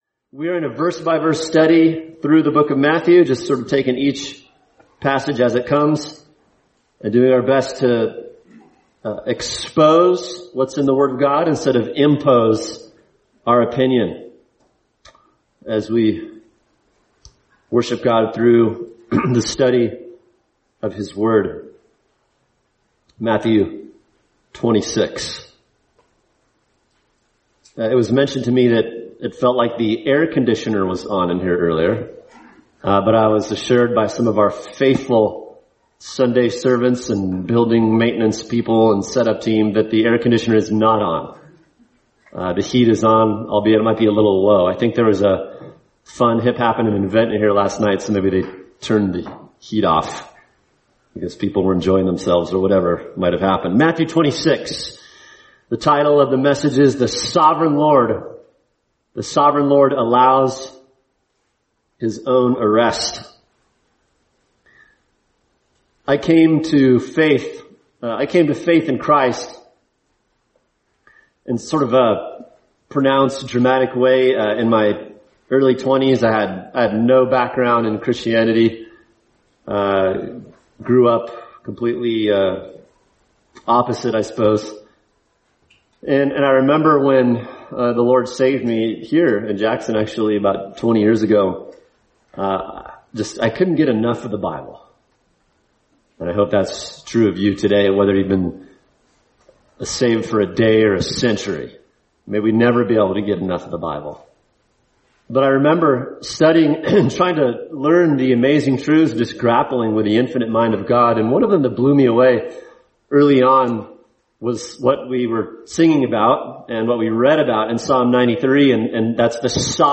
[sermon] Matthew 26:47-56 – The Sovereign Lord Allows His Own Arrest | Cornerstone Church - Jackson Hole